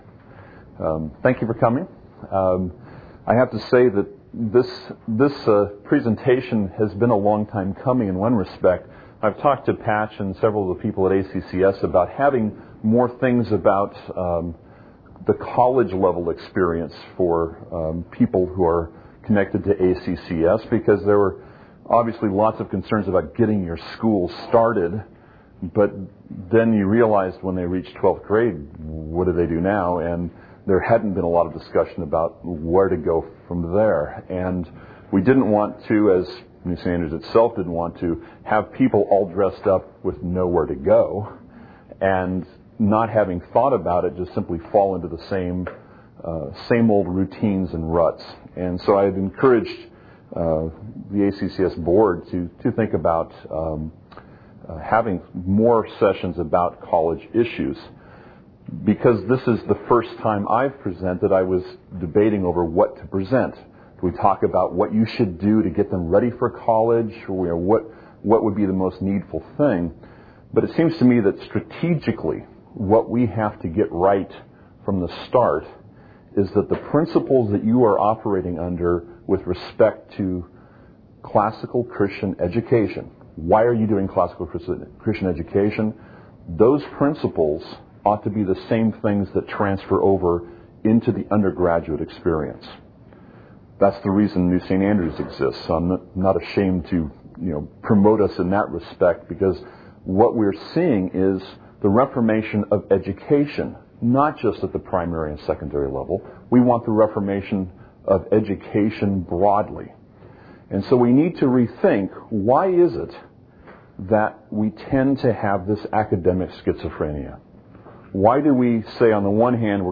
2004 Workshop Talk | 1:07:54 | 7-12